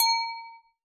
Glass.wav